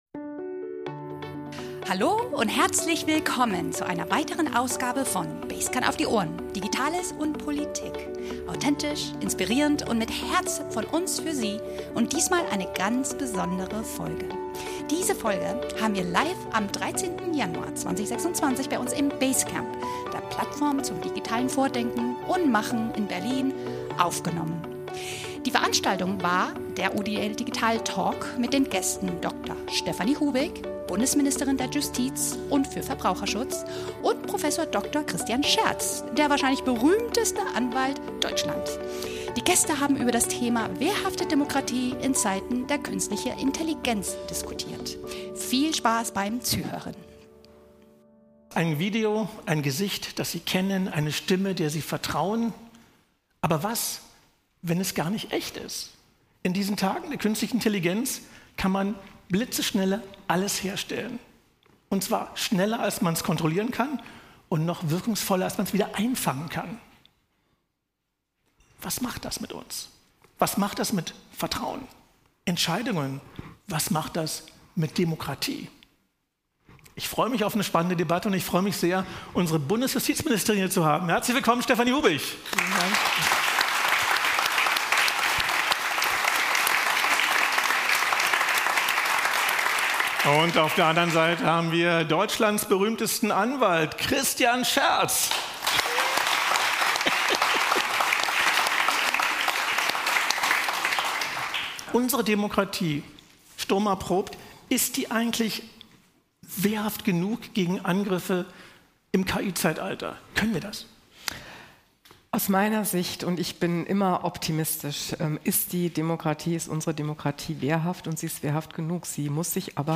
In dieser Folge zum UdL Digital Talk diskutieren wir im Berliner BASECAMP zentrale Fragen eines modernen Rechts- und Staatswesens: Wie erkennt Politik Wahrheit im Zeitalter von KI-Fakes?